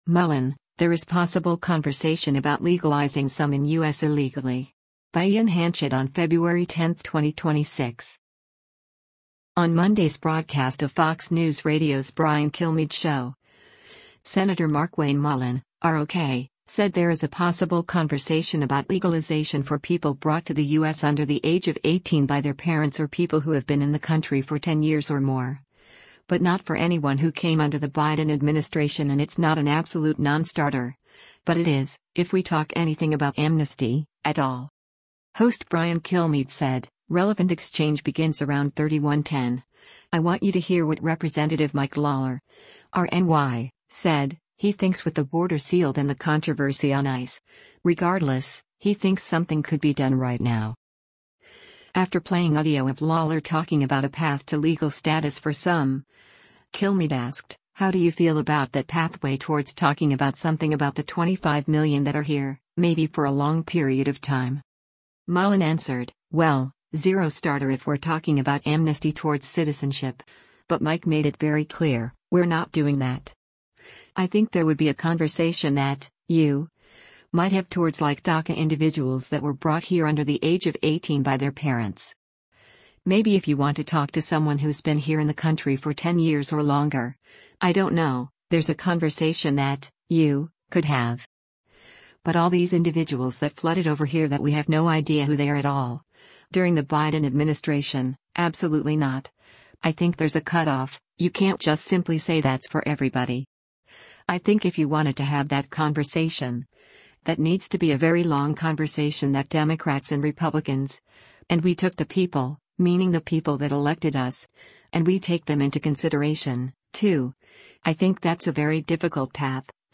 On Monday’s broadcast of Fox News Radio’s “Brian Kilmeade Show,” Sen. Markwayne Mullin (R-OK) said there is a possible conversation about legalization for people brought to the U.S. under the age of 18 by their parents or people who have been in the country for ten years or more, but not for anyone who came under the Biden administration and “it’s not an absolute nonstarter, but it is, if we talk anything about amnesty, at all.”